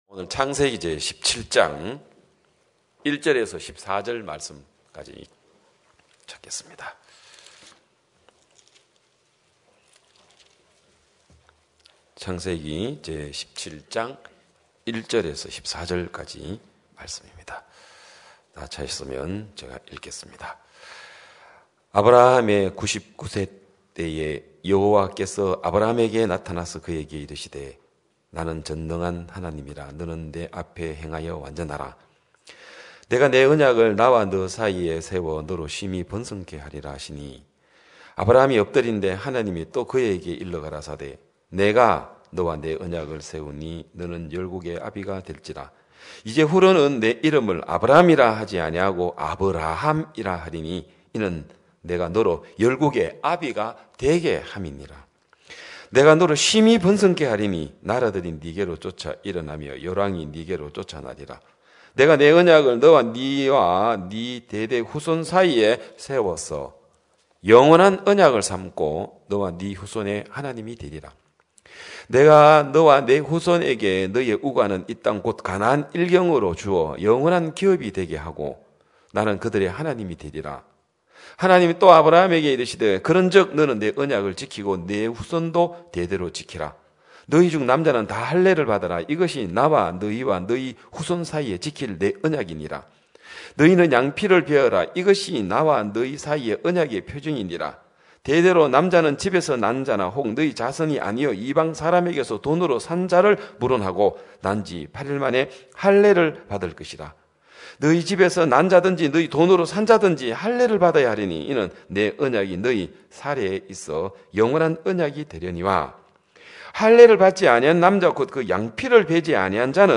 2022년 3월 6일 기쁜소식양천교회 주일오전예배
성도들이 모두 교회에 모여 말씀을 듣는 주일 예배의 설교는, 한 주간 우리 마음을 채웠던 생각을 내려두고 하나님의 말씀으로 가득 채우는 시간입니다.